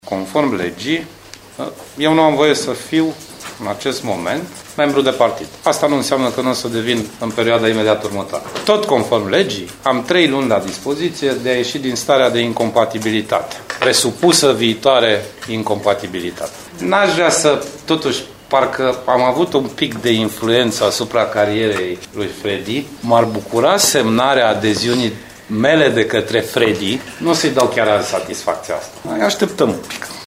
La alegerile din PSD Timiș au particiat președintele interimar al PSD, Marcel Ciolacu, dar și președintele ANCOM, Sorin Grindeanu.
Fostul premier, căruia nu îi permite legea să fie membru de partid a susținut un discurs politic, declarând că nu exclude posibilitatea de a se întoarce în perioada următoare în PSD.